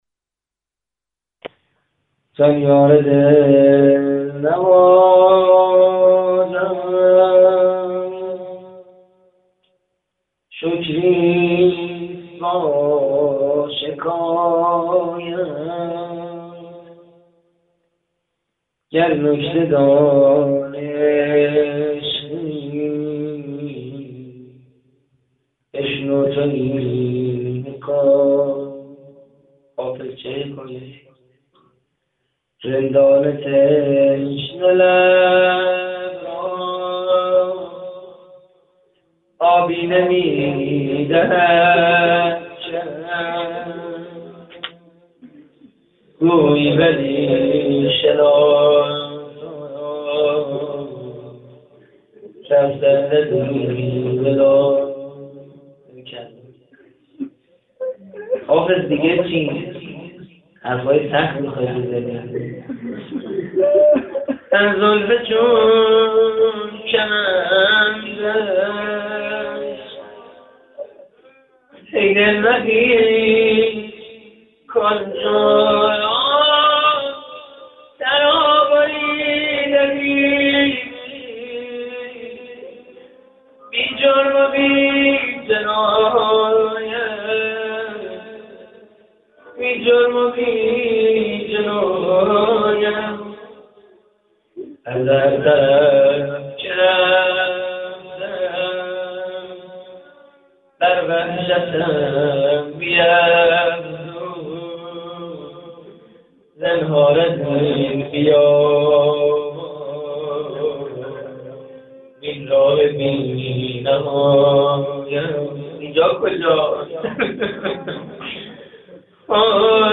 روضه: زان یار دلنوازم
مراسم روضه هفتگی هیئت کانون دانش آموزی کانون امام حسن مجتبی (ع)